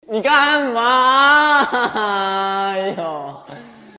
人群拍手鼓掌声
描述：人群拍手鼓掌声，带有欢呼声
Tag: 人群 掌声 拍手 观众 欢呼